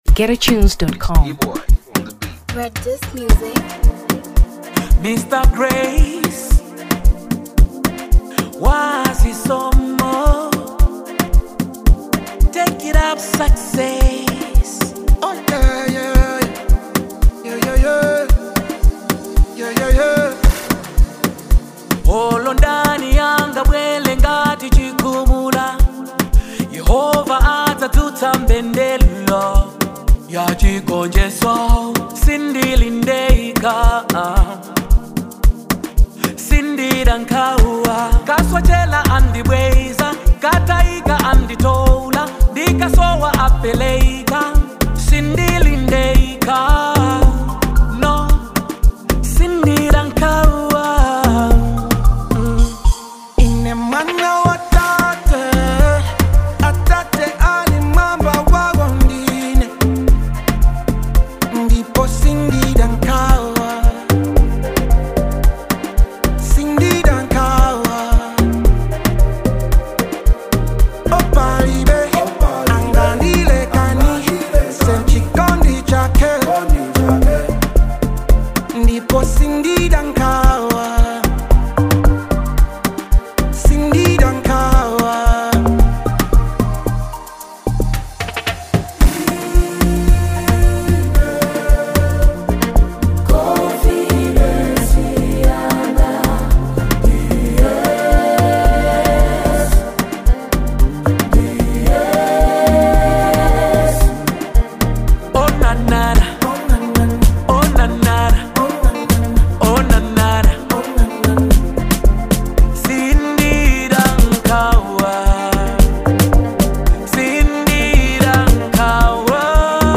Gospel Amapiano 2023 Malawi